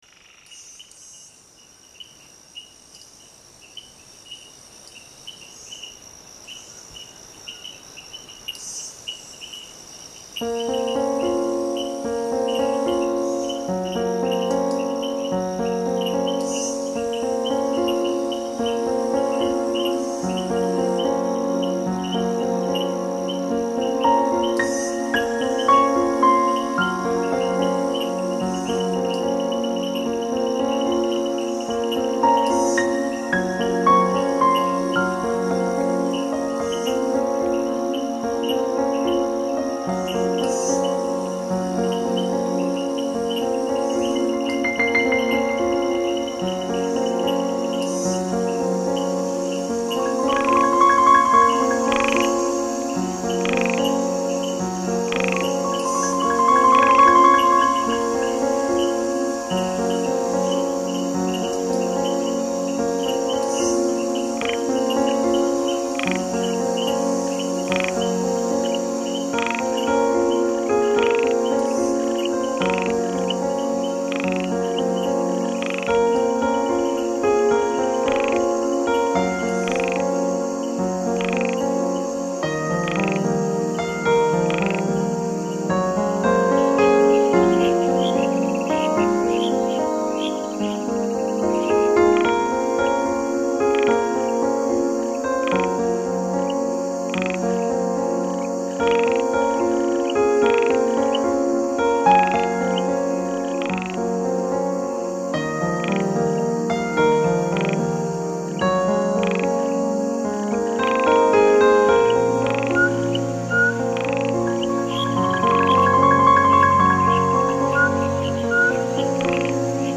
乐风： 放鬆
收录娃娃谷、白河、扇平、福山、兰屿、擎天岗、初鹿...台湾夜晚自然天籁
阳明山暮蝉、翡翠树蛙、牛蛙、盘古蟾蜍、黑冠麻鹭、山羌、黄嘴角枭、飞鼠、竹林晚风............
还有吉他的轻柔、打击乐的欢快、钢琴的纤细、双簧管的优雅、笛箫的悠扬......